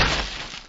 ballbounceIce4.wav